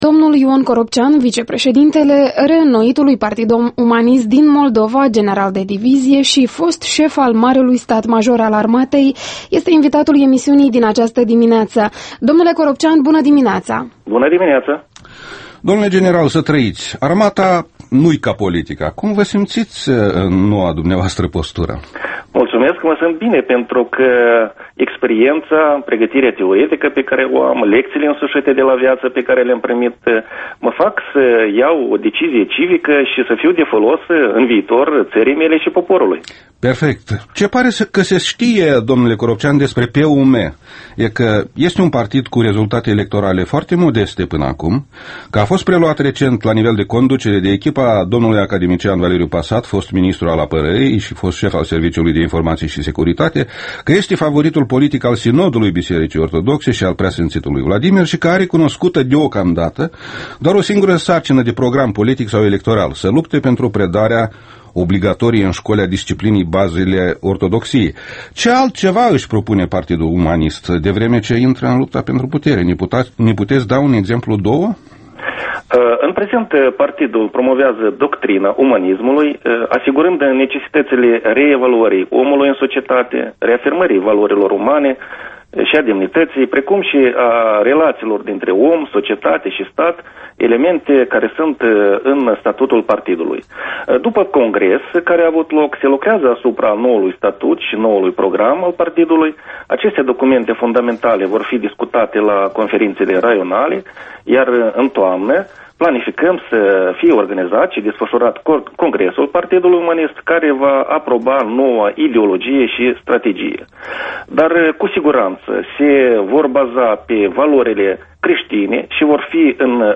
Interviu matinal EL